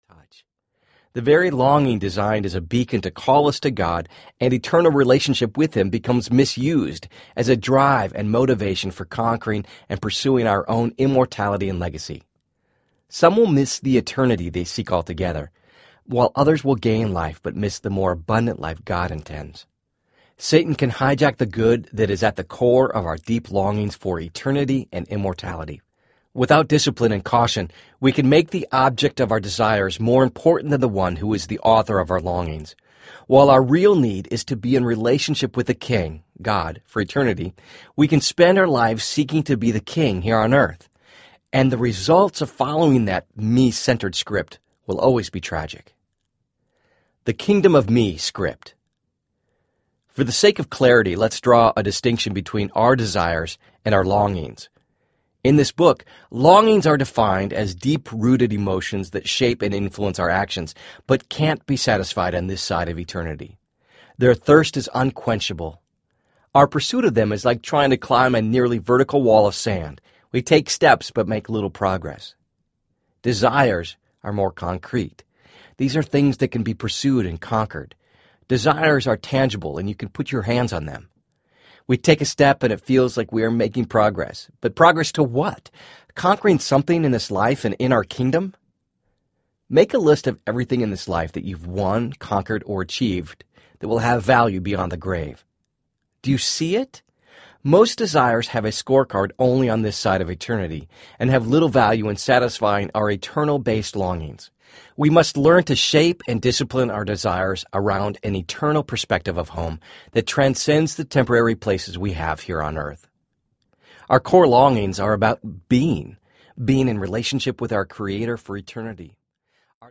More Audiobook